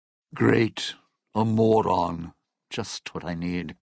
Category: Dead Money audio dialogues Du kannst diese Datei nicht überschreiben.